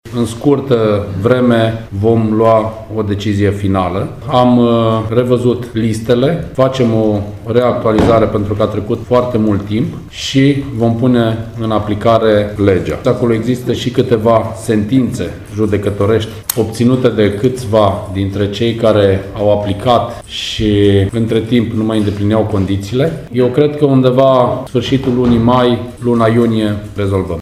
Viceprimarul Timișoarei, Cosmin Tabără, spune că reactualizarea este necesară pentru că a trecut foarte mult timp, precizând că există și câteva sentințe judecătorești obținute de câțiva aplicanți care nu mai îndeplineau condițiile necesare.